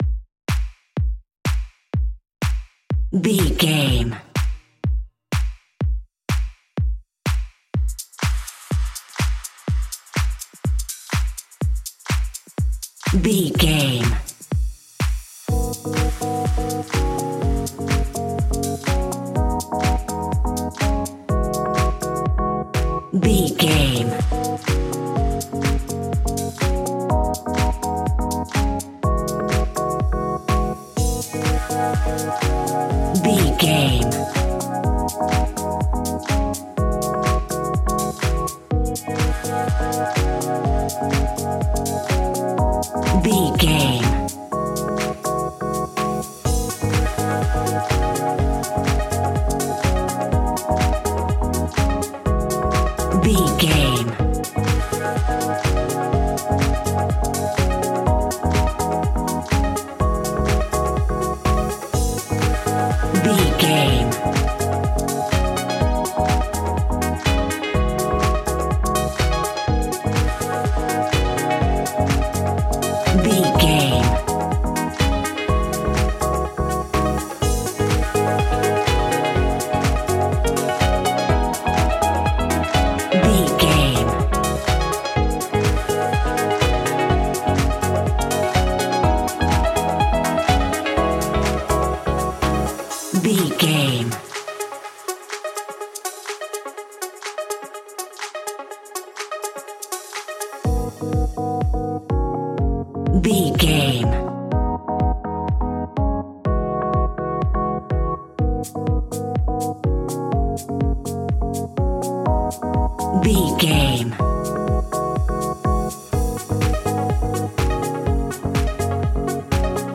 Aeolian/Minor
G#
groovy
uplifting
futuristic
driving
energetic
bouncy
synthesiser
drum machine
electro house
funky house
house instrumentals
synth leads
synth bass